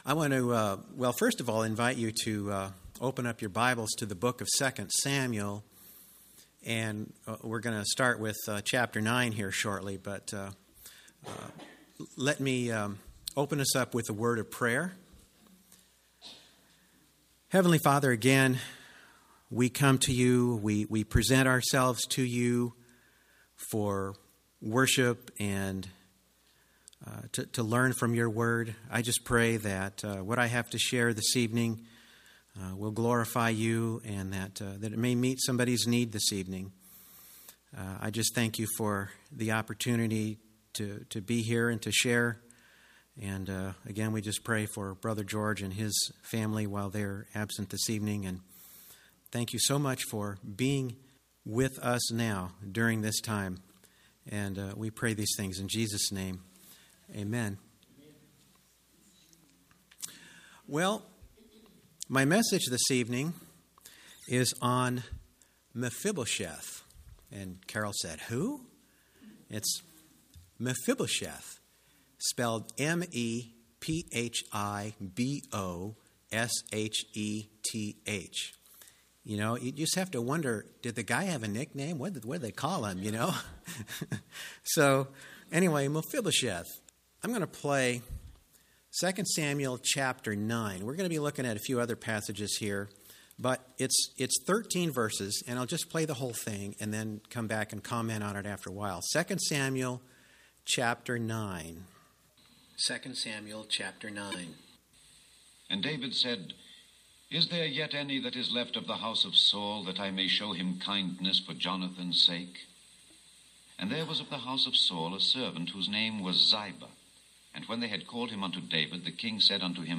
On Sunday evening, November 17, 2019, I was privileged to share Mephibosheth’s story at our church–Northwood Baptist Church, Columbia, MO.